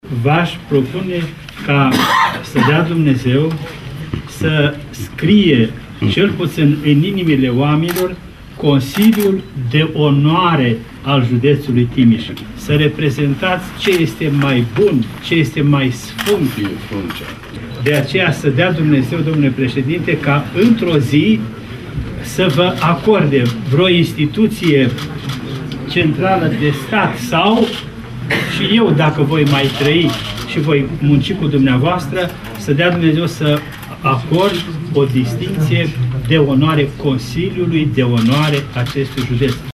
Ioan-Selejan-Mitropolit-al-Banatulu.mp3